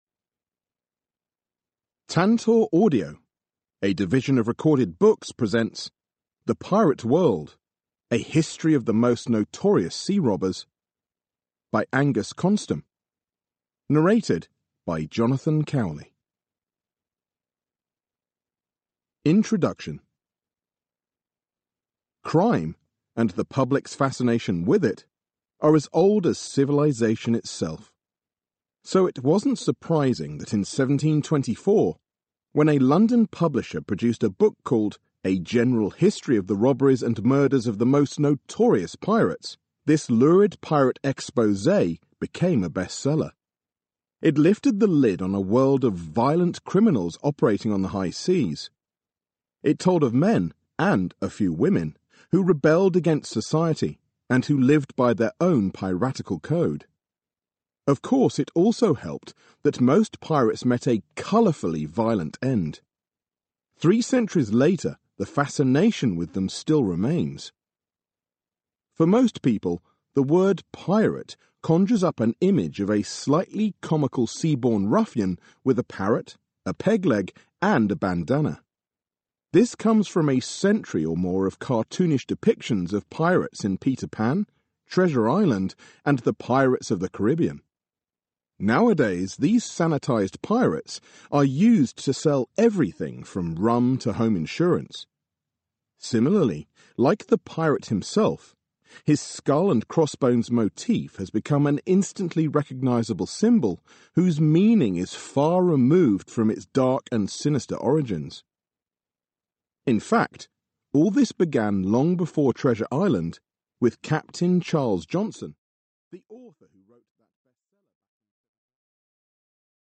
digital digital digital stereo audio file Notes